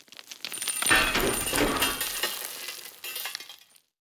expl_debris_mtl_01.ogg